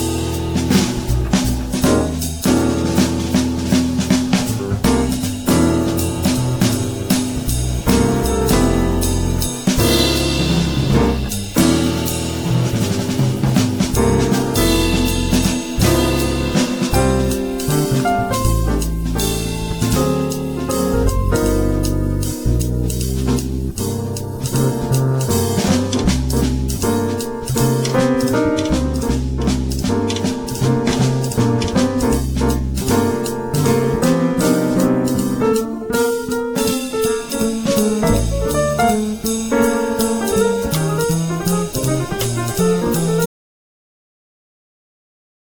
jazz piano trio
bass
drums